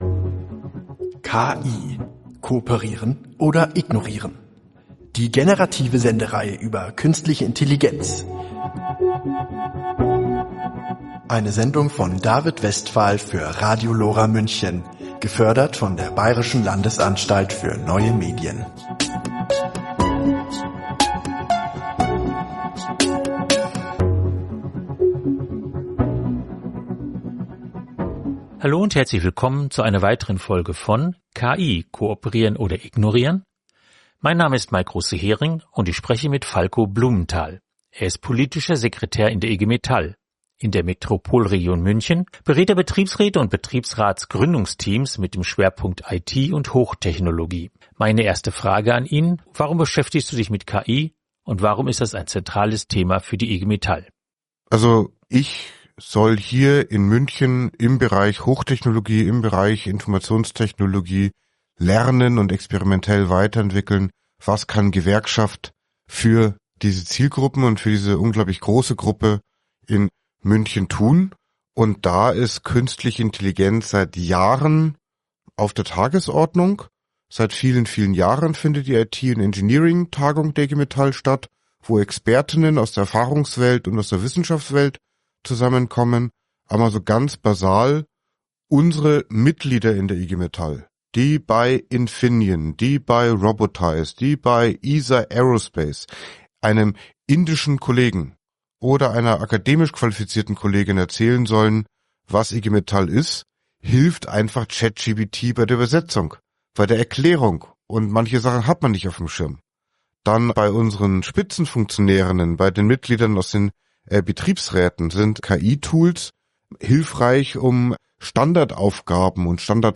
KI in der Arbeitswelt, Gespräch